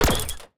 UIClick_Menu Strong Metal Rustle 02.wav